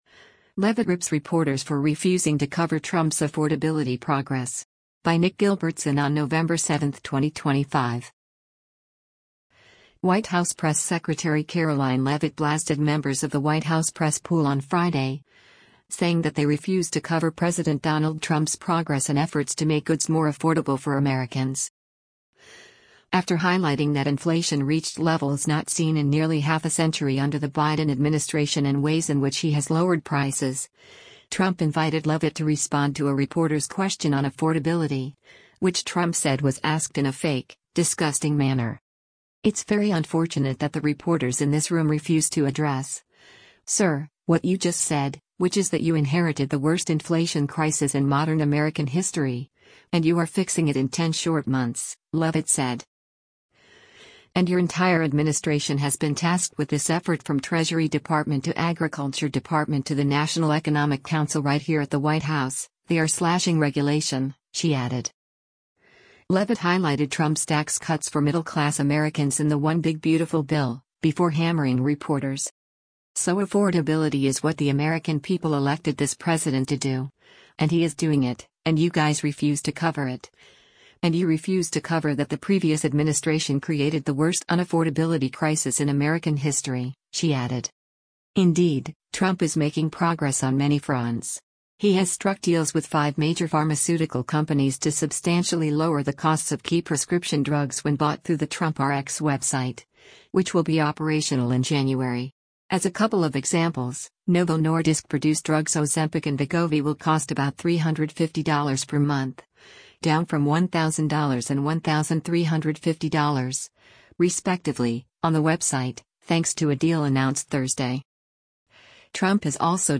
White House press secretary Karoline Leavitt speaks during the daily press briefing in the